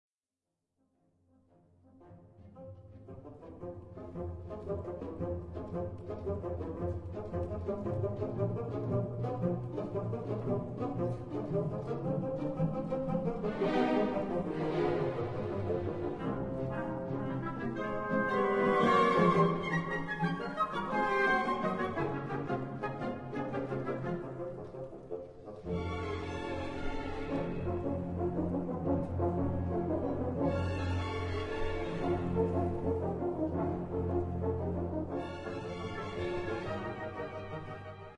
Scherzo